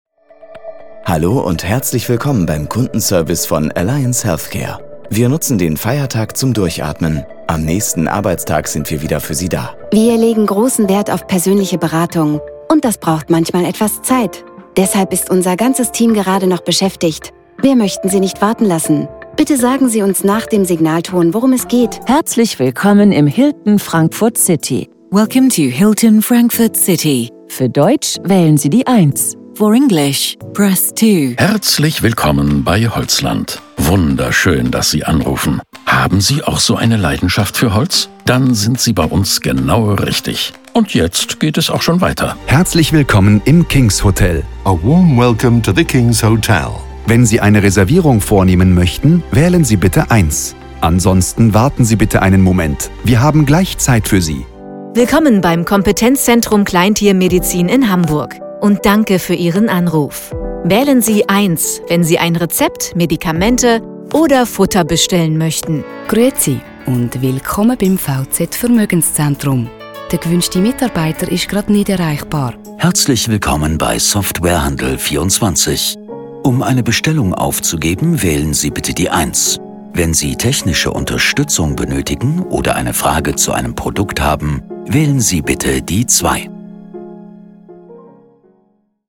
Seriös und klar für den perfekten Business-Auftritt